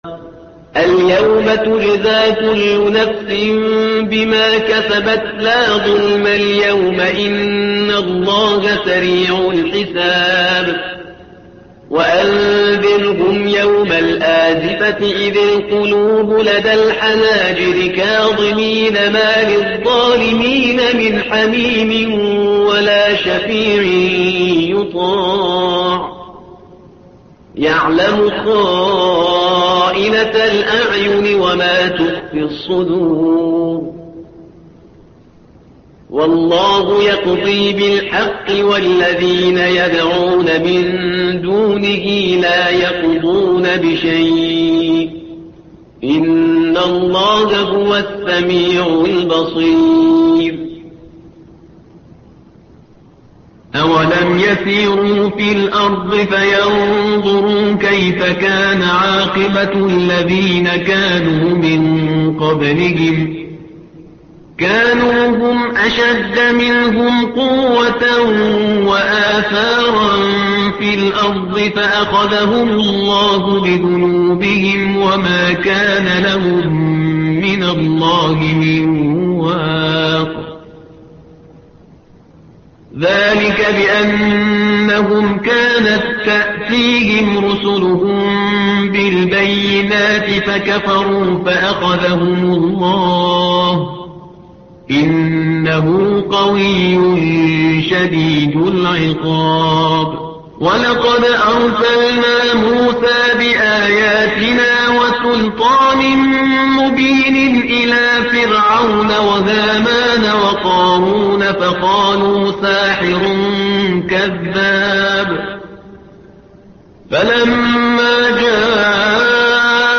تحميل : الصفحة رقم 469 / القارئ شهريار برهيزكار / القرآن الكريم / موقع يا حسين